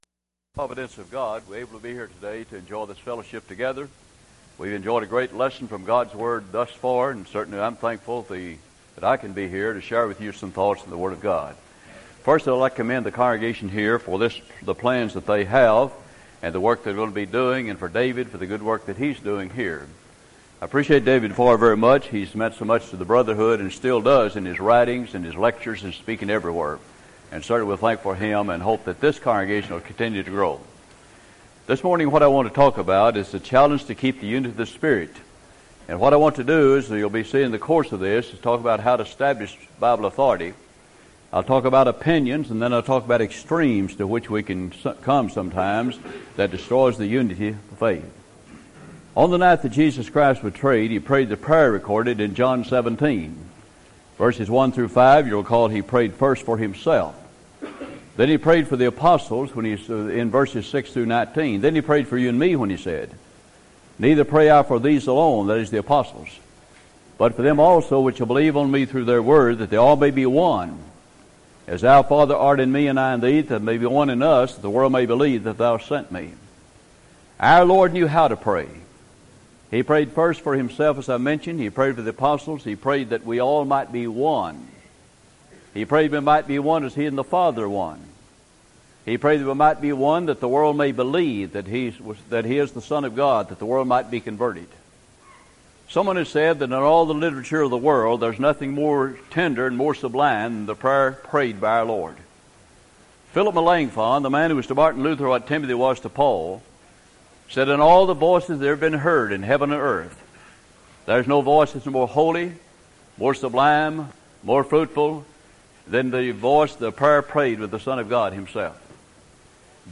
Event: 1999 Carolina Men's Fellowship
lecture